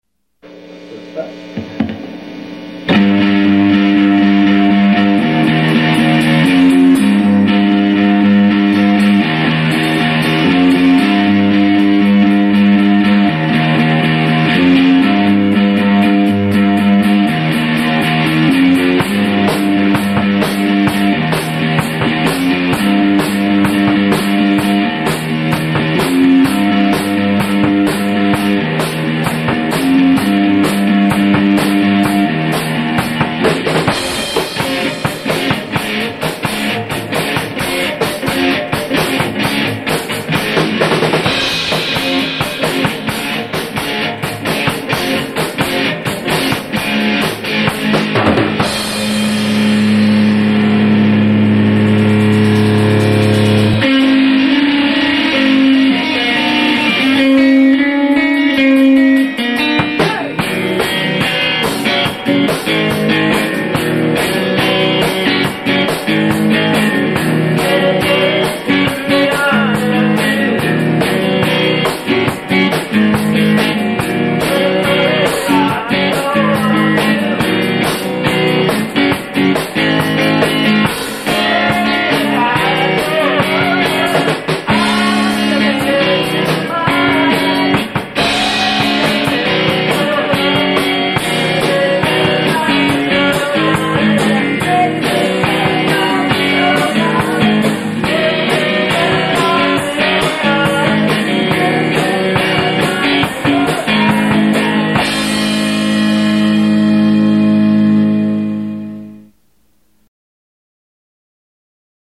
They are completely and unapologetically live.